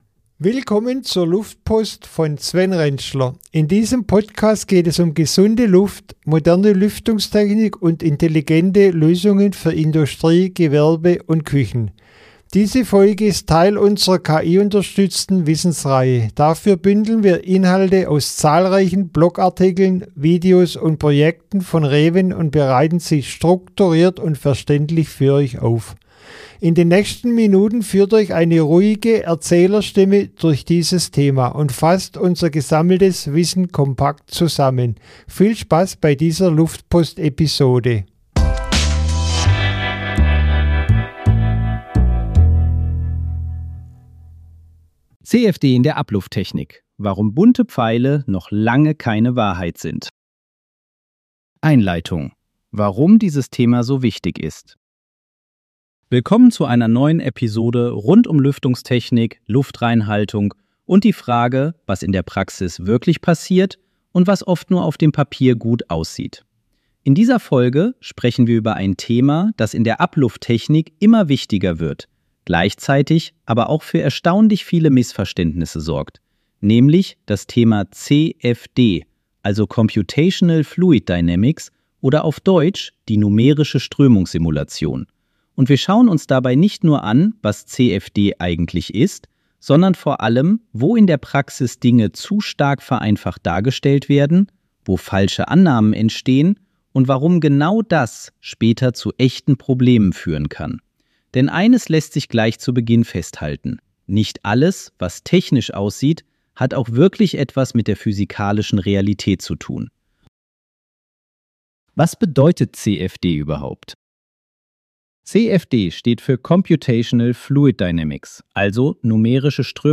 In dieser KI-gestützten Podcast-Episode sprechen wir über ein Thema, das in der Ablufttechnik und Lüftungstechnik immer wichtiger wird und gleichzeitig häufig falsch verstanden wird: CFD, also die numerische Strömungssimulation.